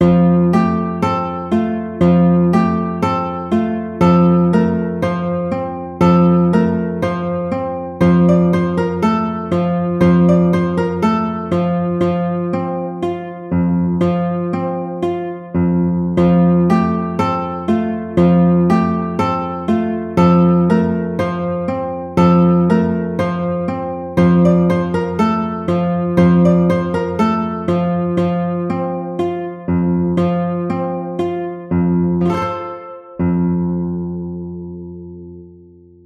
The 11-String Alto Guitar
FrereJacquesAlto.mp3